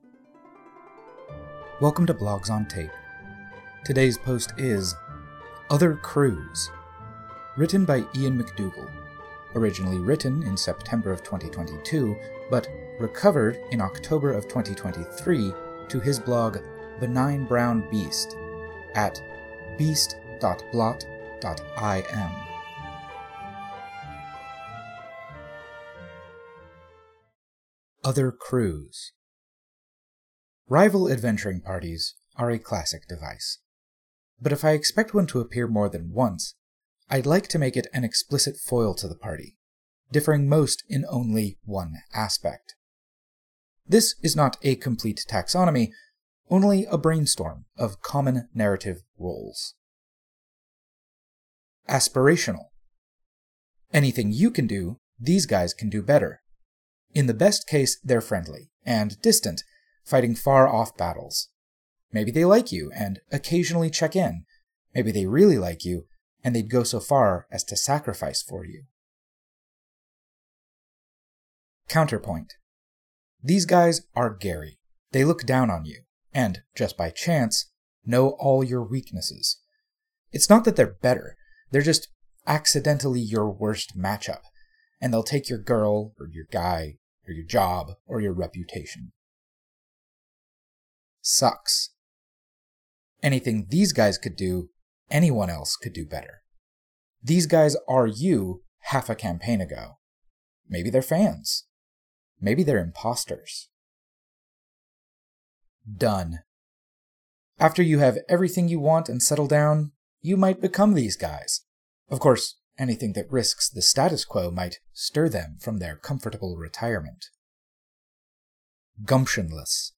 OSR Blog Posts. Read out loud.